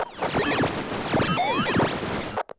nerfs_psynoise7.ogg